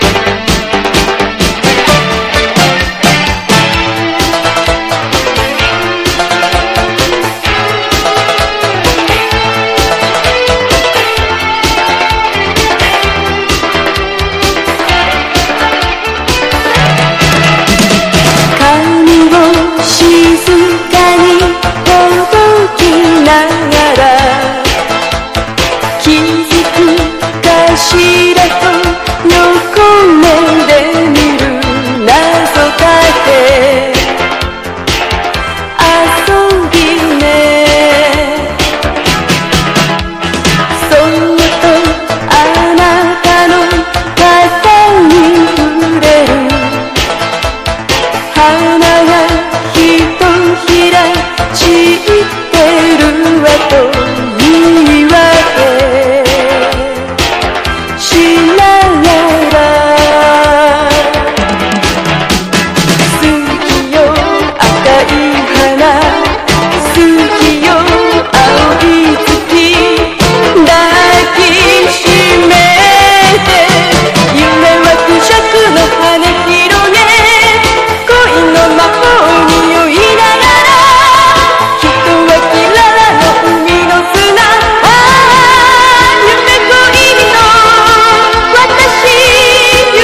POP
ポピュラー# 70-80’S アイドル